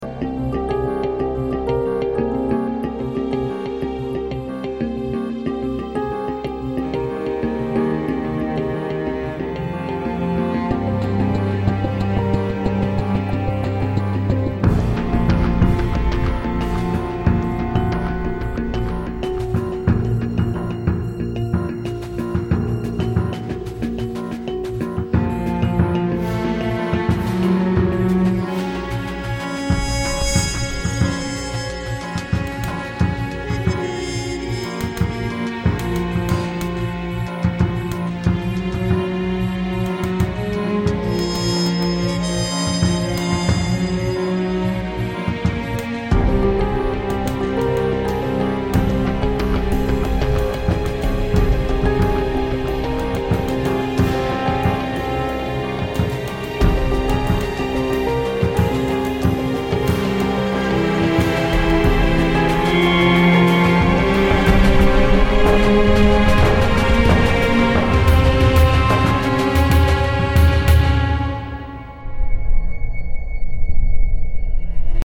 The mysterious and bone-chilling score